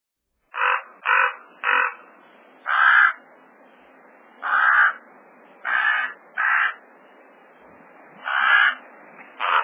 turkey vulture